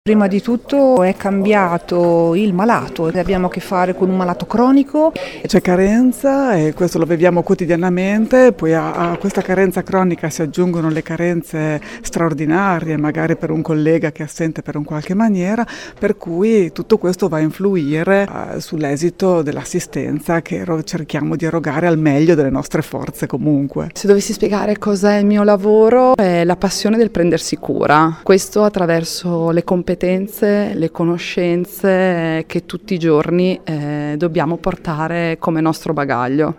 Alcune infermiere modenesi:
infermieri-vox.mp3